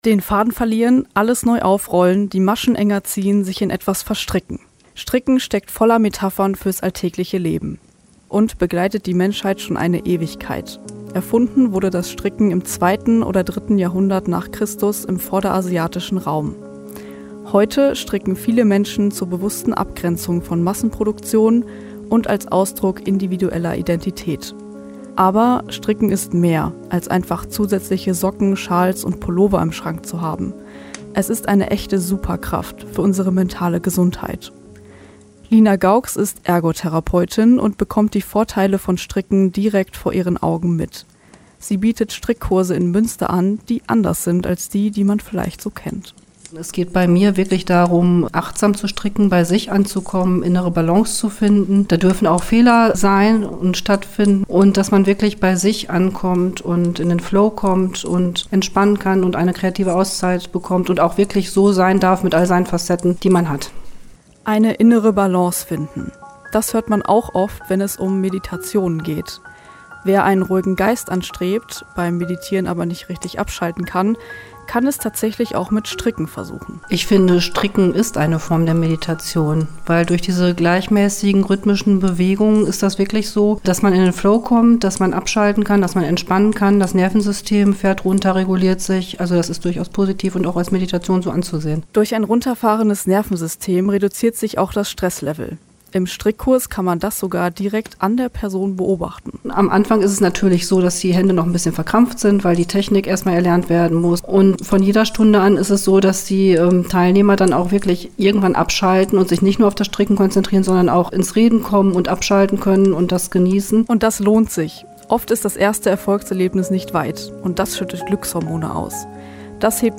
In diesem Radiobeitrag über die ‚Superpower des Strickens‘ komme ich als Expertin zu Wort und spreche über die Verbindung von Stricken, Achtsamkeit und persönlicher Entwicklung.
Zum Radiobeitrag